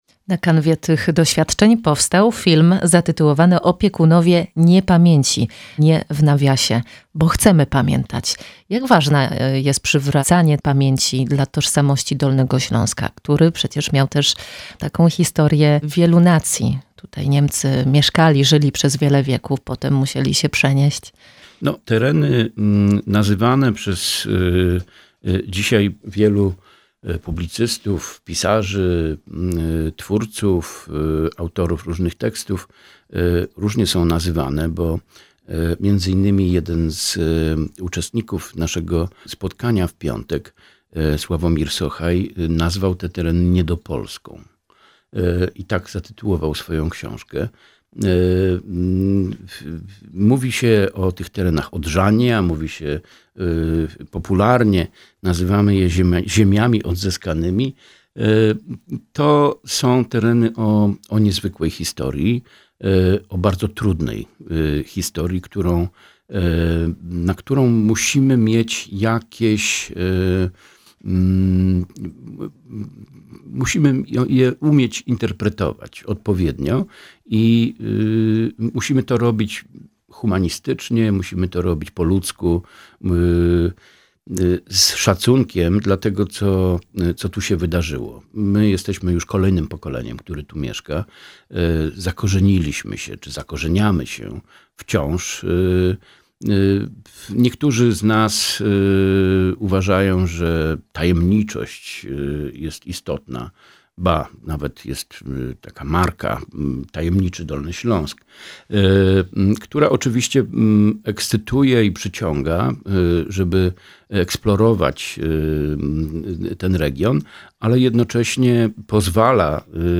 Cała rozmowa w piątek 5 grudnia w audycji „Przystanek Kultura” po godz. 10:10.